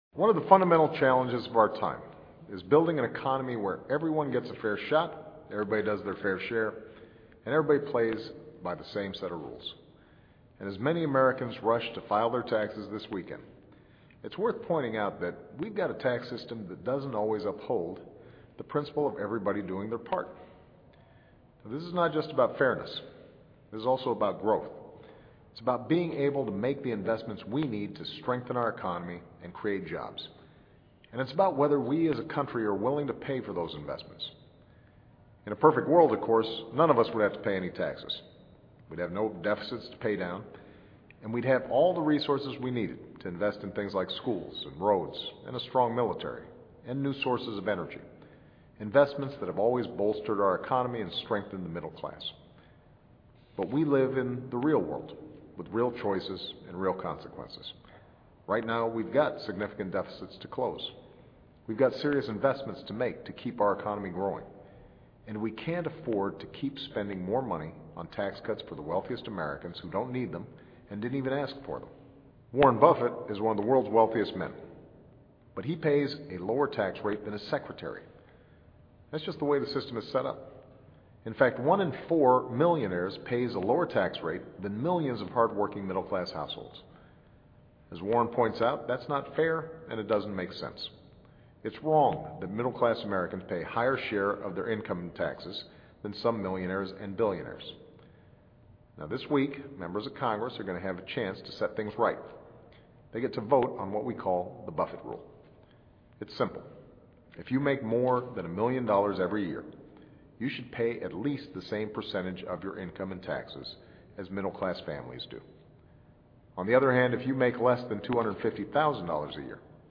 奥巴马总统每周电台演讲:呼吁通过《巴菲特条例》 听力文件下载—在线英语听力室